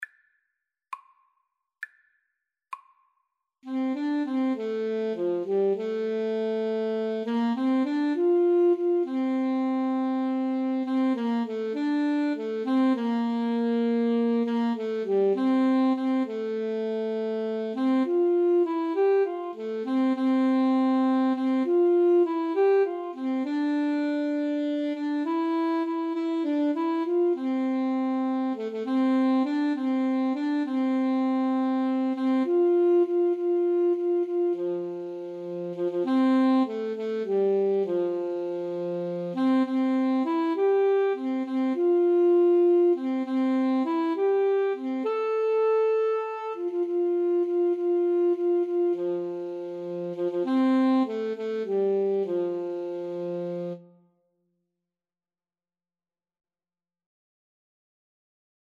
6/8 (View more 6/8 Music)
Classical (View more Classical Saxophone-Cello Duet Music)